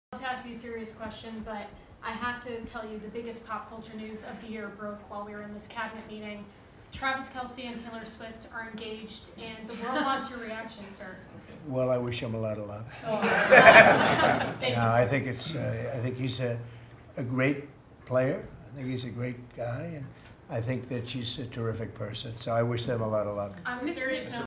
Ha risposto così Donald Trump ai giornalisti alla Casa Bianca che hanno chiesto un commento del presidente americano sull'annuncio del matrimonio tra la popstar Taylor Swift e il campione di football americano Travis Kelce.